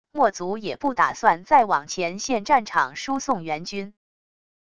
墨族也不打算再往前线战场输送援军wav音频生成系统WAV Audio Player